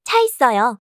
audio_car_watchout.wav